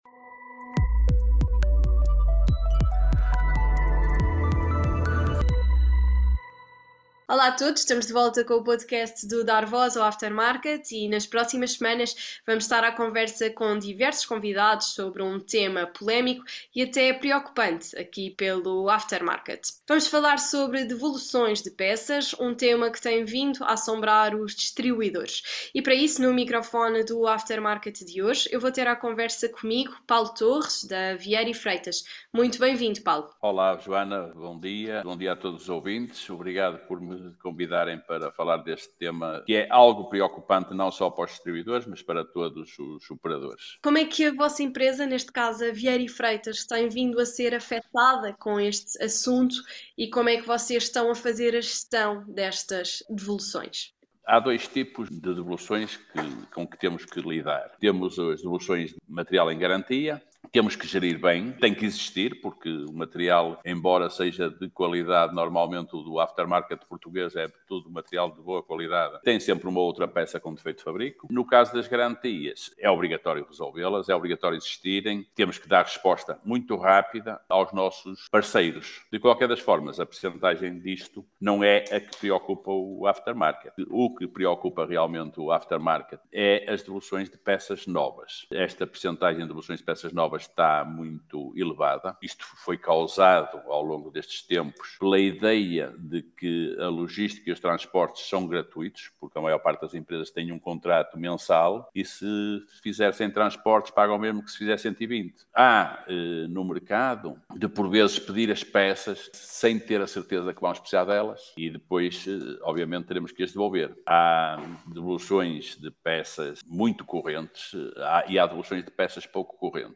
Until the end of April and during the month of May Journal das Oficinas will interview various guests from the sector discussing a topic that has been recently haunting distributors: ‘part returns.’